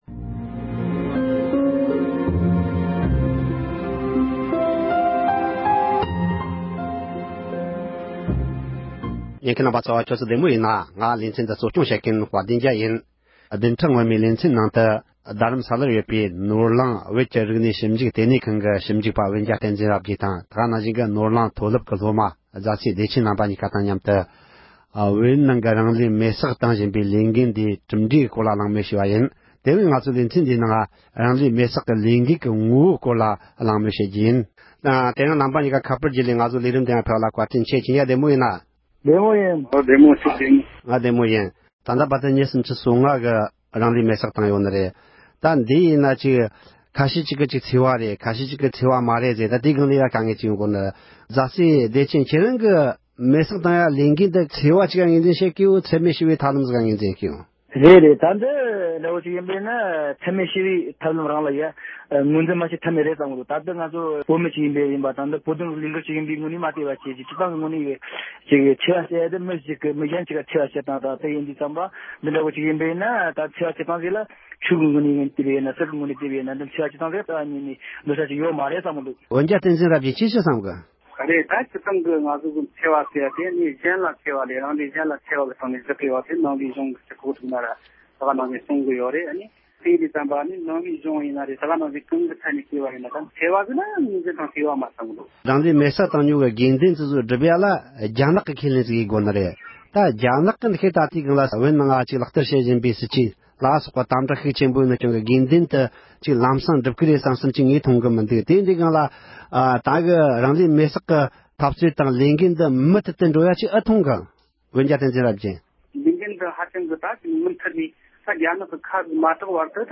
རང་ལུས་མེ་སྲེག་གི་ལས་འགུལ་སྐོར་འབྲེལ་ཡོད་མི་སྣ་དང་མུ་མཐུད་ནས་གླེང་མོལ་ཞུས་པ།